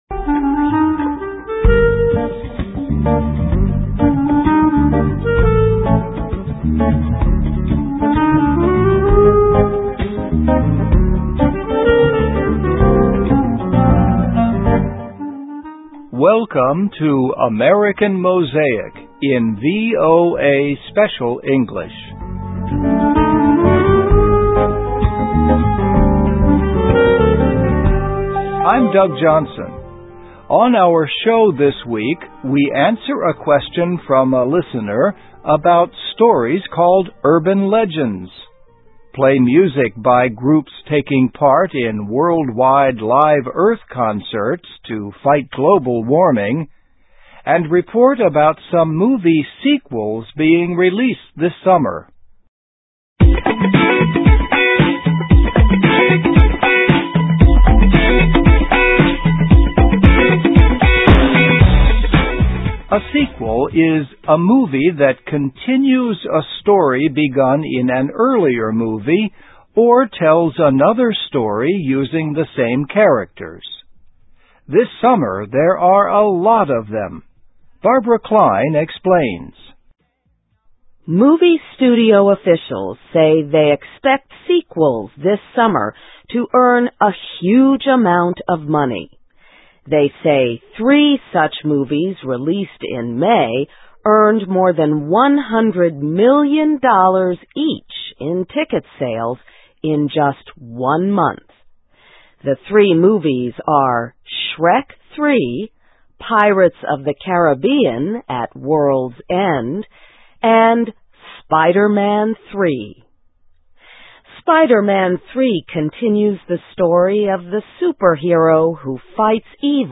Listen and Read Along - Text with Audio - For ESL Students - For Learning English
Play music by groups taking part in worldwide Live Earth concerts to fight global warming…